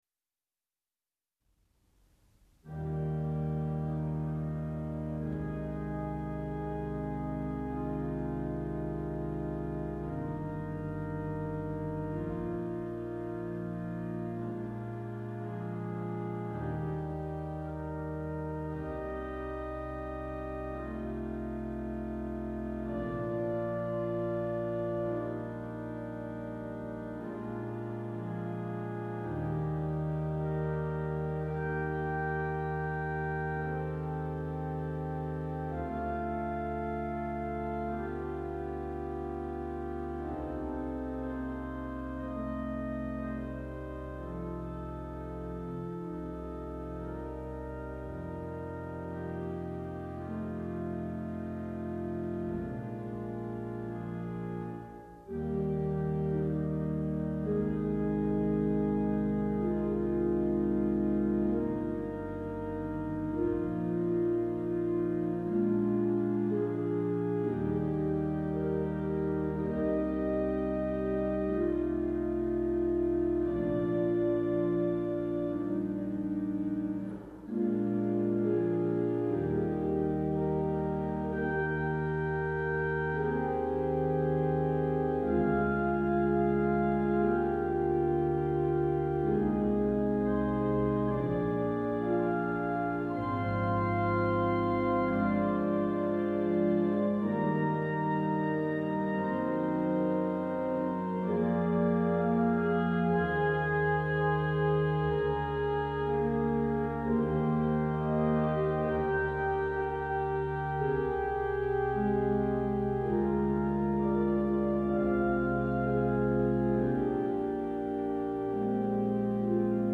Click here to listen to a performance of "Nimrod", which I will be playing at the Remembrance Day service on Nov 14th.